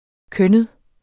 Udtale [ ˈkœnəð ]